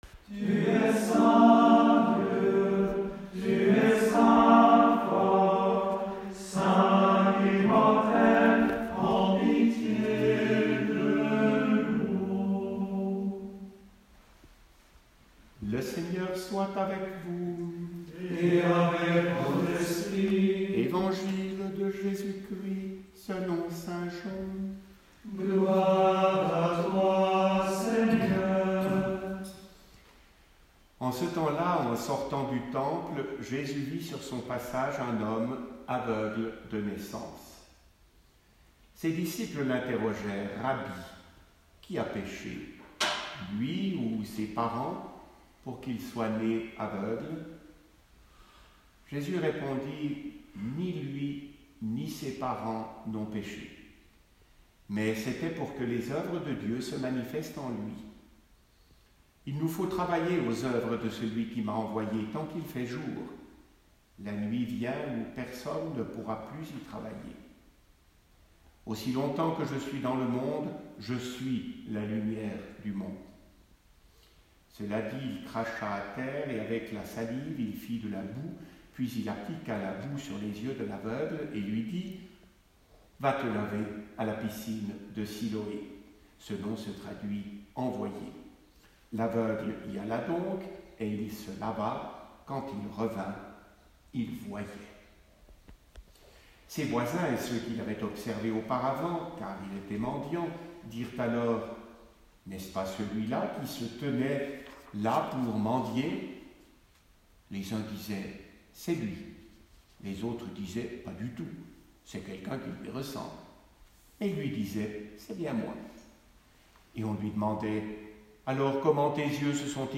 Aujourd'hui, pour la deuxième fois, nous avons célébré la messe à huis clos dans notre église.